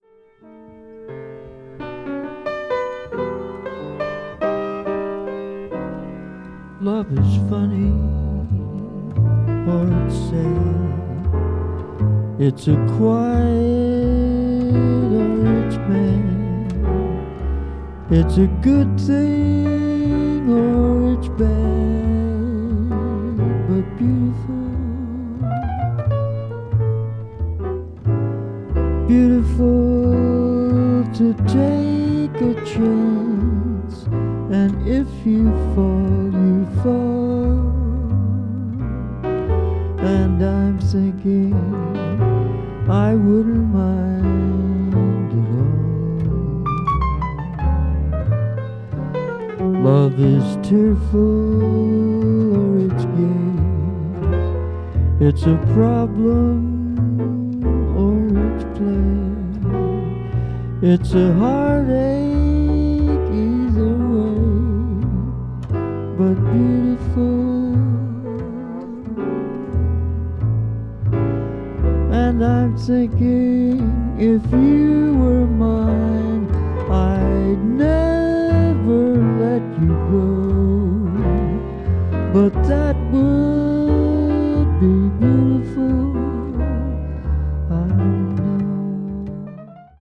柔らかでシンプルなトランペットの旋律
contemporary jazz   jazz standard   modal jazz   modern jazz